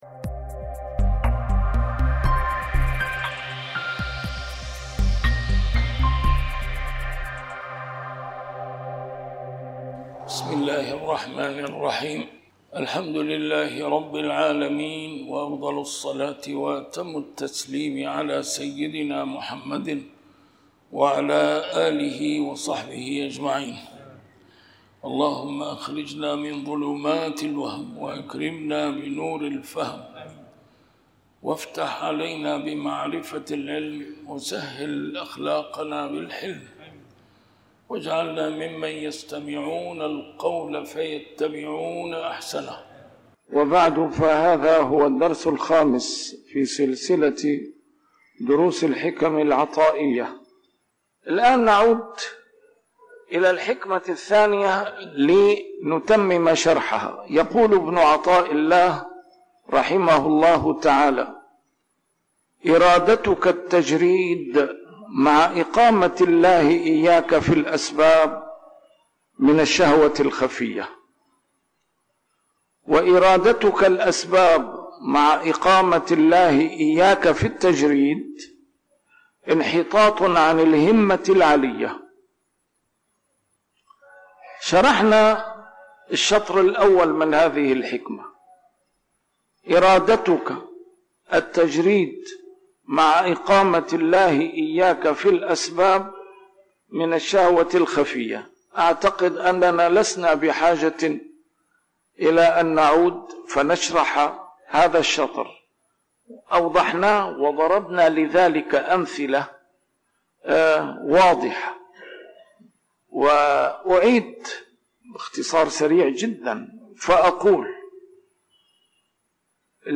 الدرس رقم 5 شرح الحكمة 2